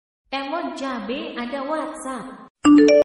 notifikasi wa nya🙏